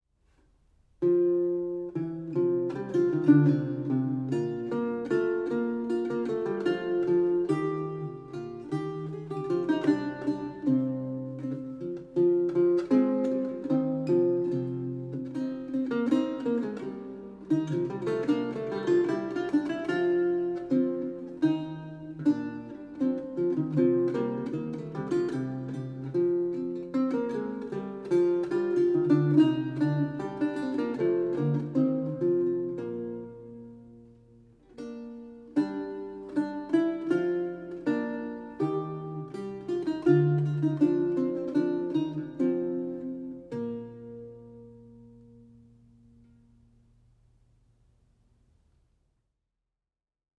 a 16th century lute music piece originally notated in lute tablature